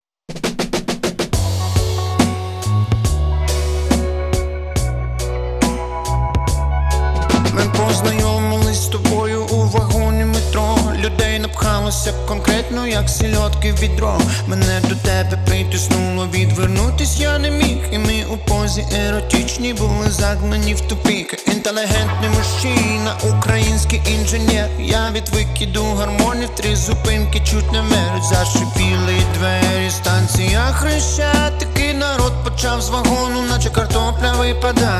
Жанр: Поп музыка / Украинские